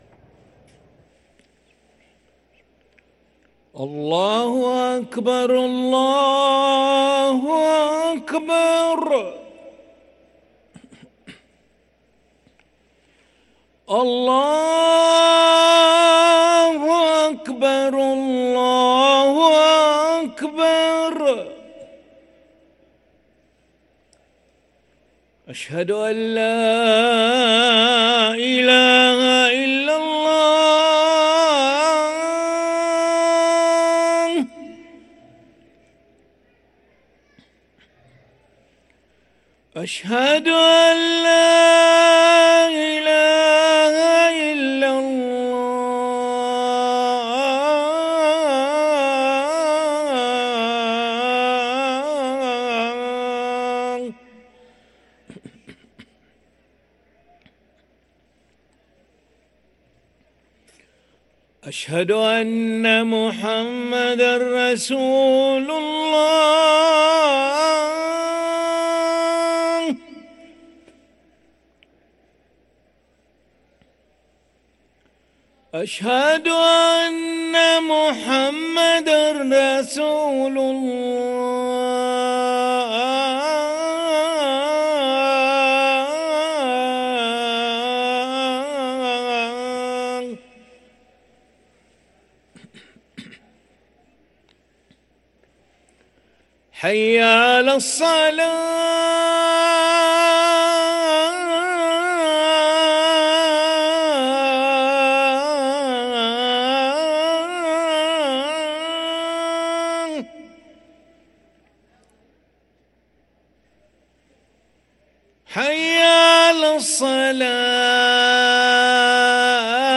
أذان العشاء للمؤذن علي أحمد ملا الخميس 12 ذو القعدة 1444هـ > ١٤٤٤ 🕋 > ركن الأذان 🕋 > المزيد - تلاوات الحرمين